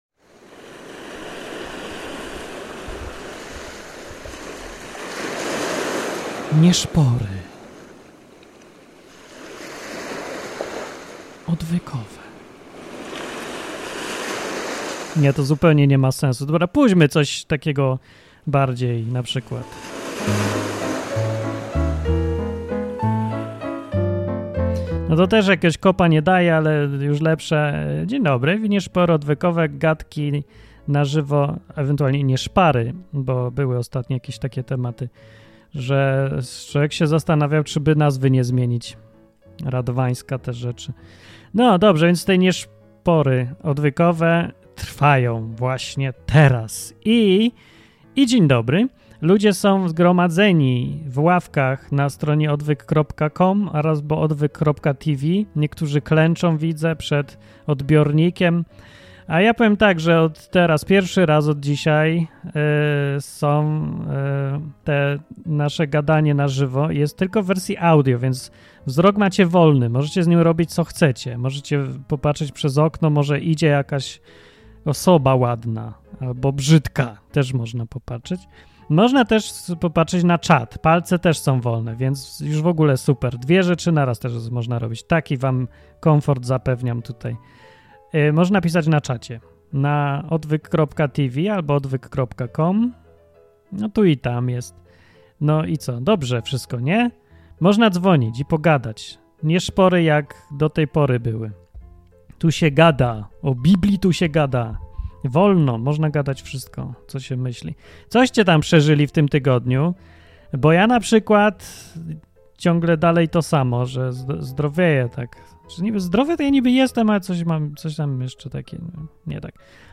Wtorkowe rozmowy na żywo ze słuchaczami.